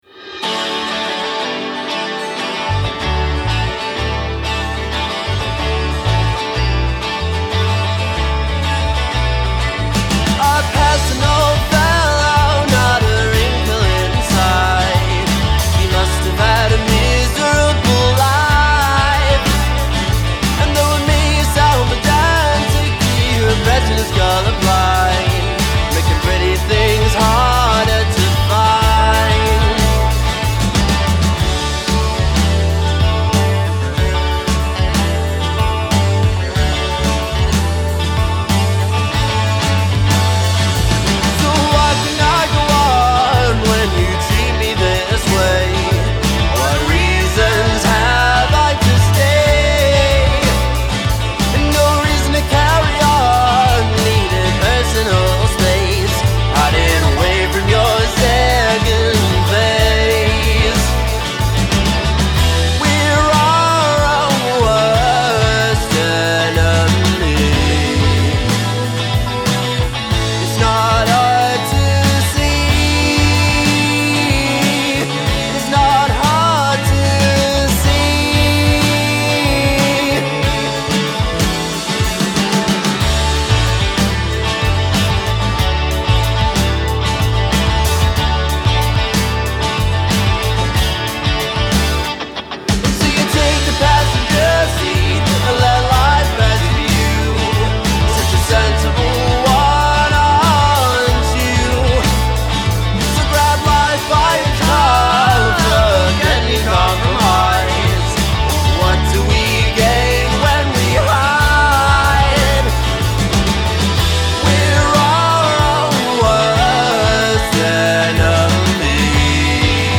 non-binary solo singer-songwriter
rich & jangly Johnny Marr tinged guitar arrangement
heartfelt vocal delivery